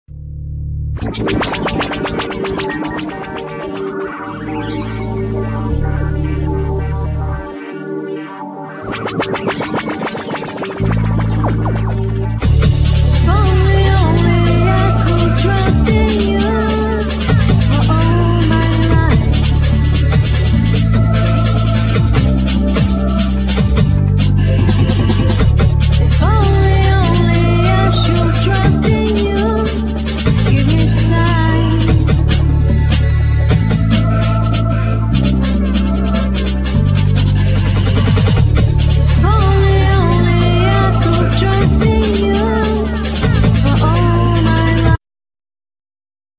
Synthsizer,Drum programming,Guitar
Sampling,Sax,Trumpet